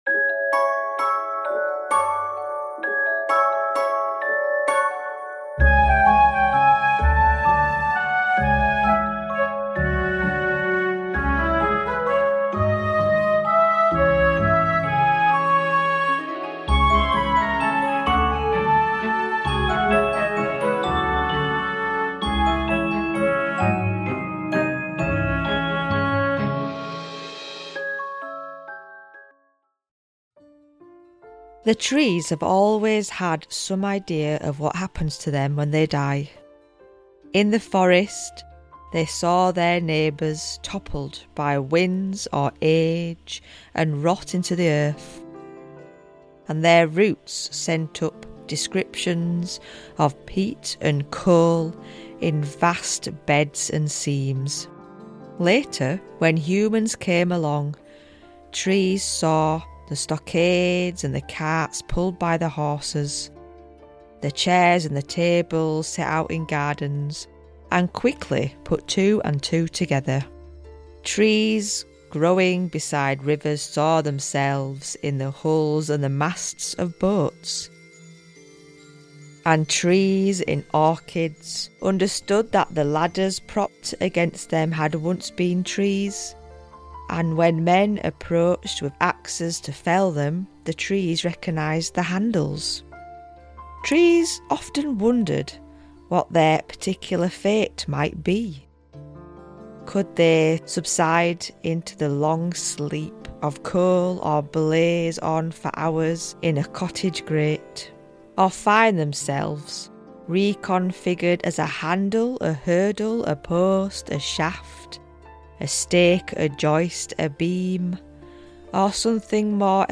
Folklore tales about trees told by a green witch inspired by nature
This is a calming episode that invites the listener to connect with trees today and allow them to assist ones witchcraft.